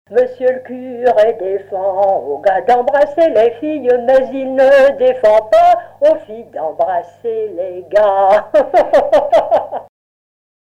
Mémoires et Patrimoines vivants - RaddO est une base de données d'archives iconographiques et sonores.
danse : polka
Genre strophique
Pièce musicale inédite